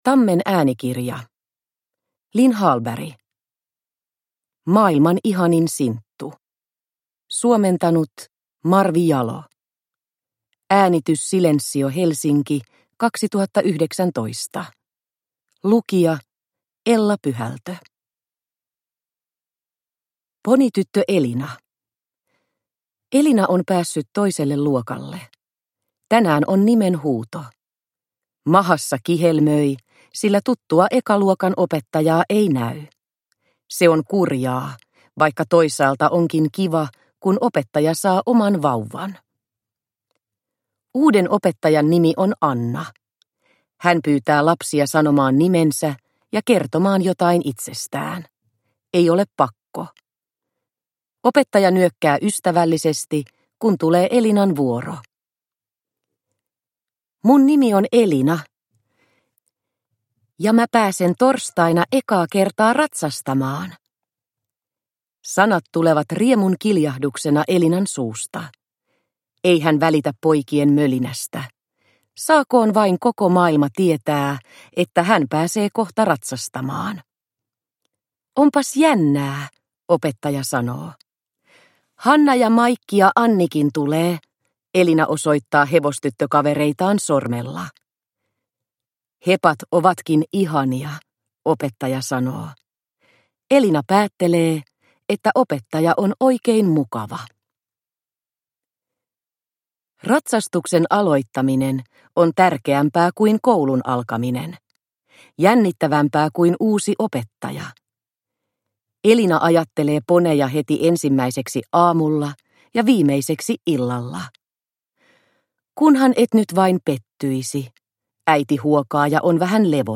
Maailman ihanin Sinttu – Ljudbok – Laddas ner